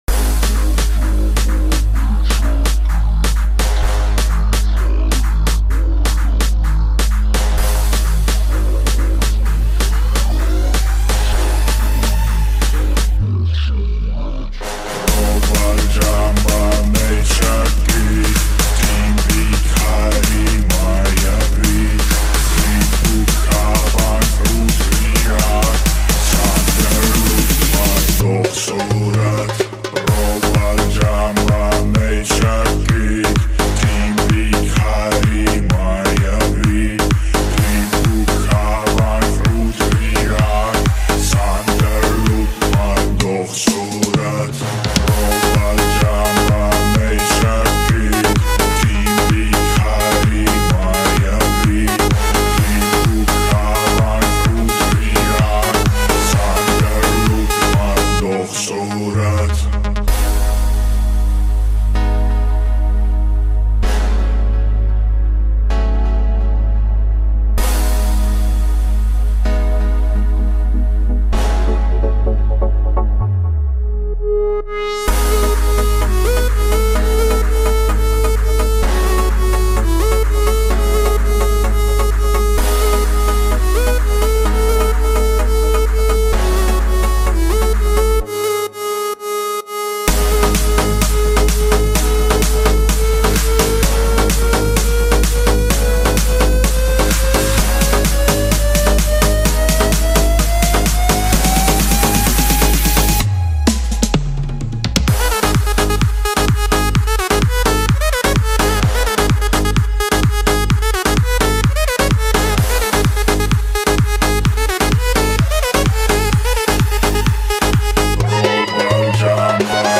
Club Mix 2022